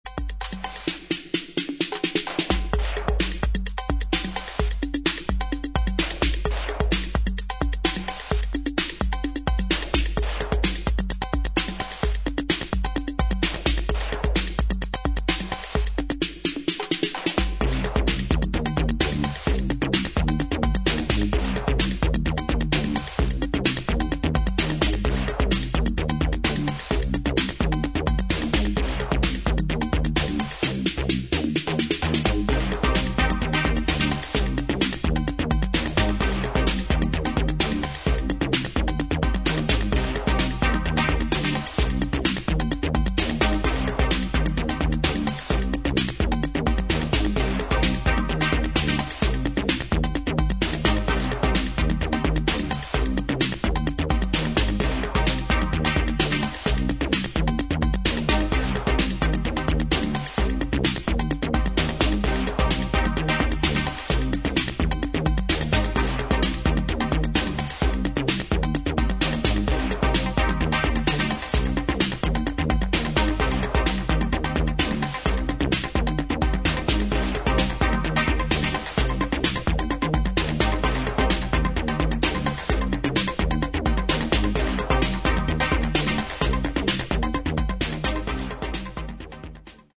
今聴くとレトロ・フューチャーな感触を覚える作品です。